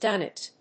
/ˈdʌnɪt(米国英語)/